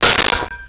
Metal Lid 05
Metal Lid 05.wav